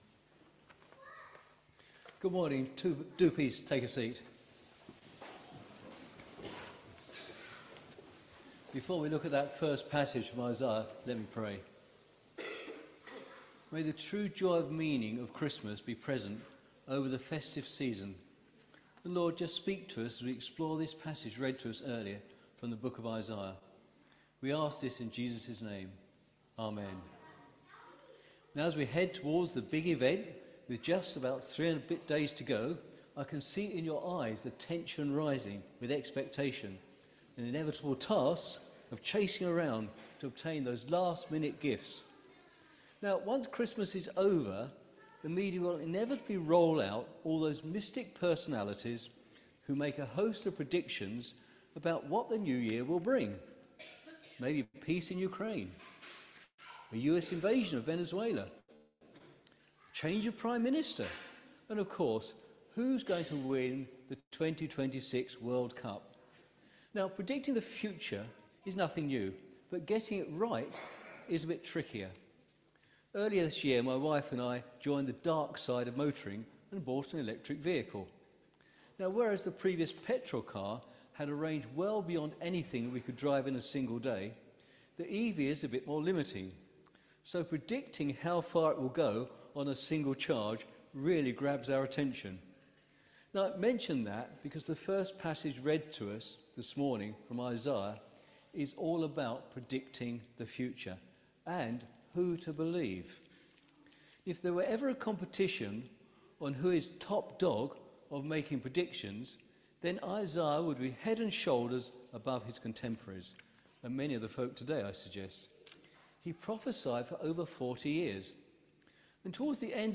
This is the word of the Lord All Thanks be to God Series: Advent and Christmas , Sunday Morning